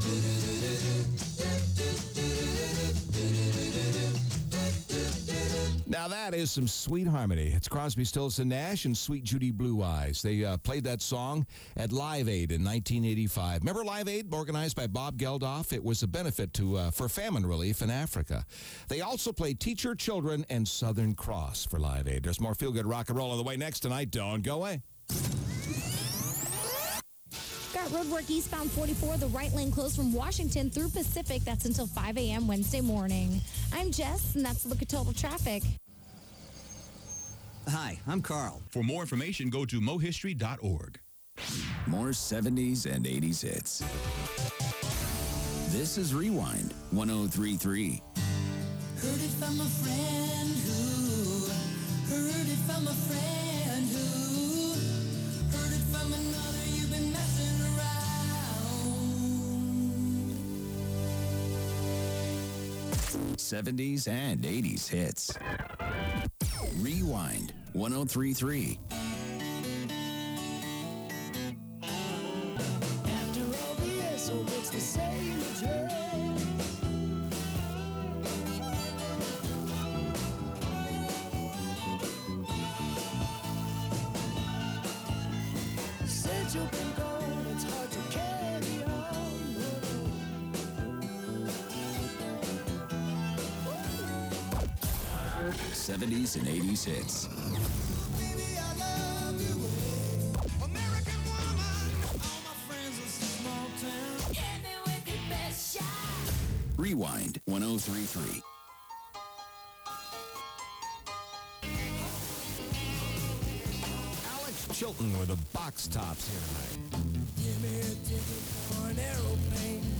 KLOU Unknown AIrcheck · St. Louis Media History Archive